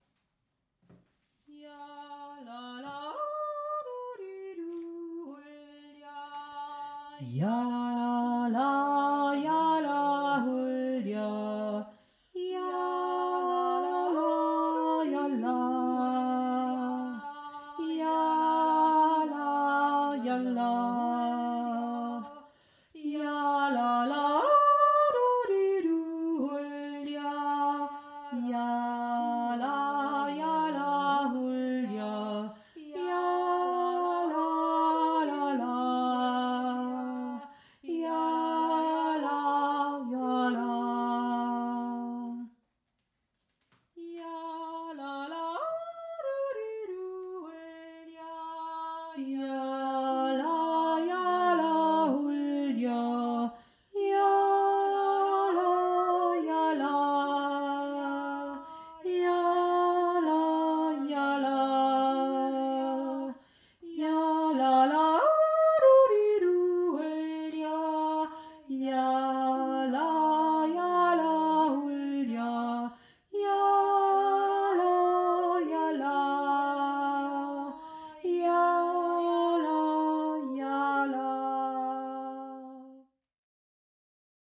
tiefe Stimme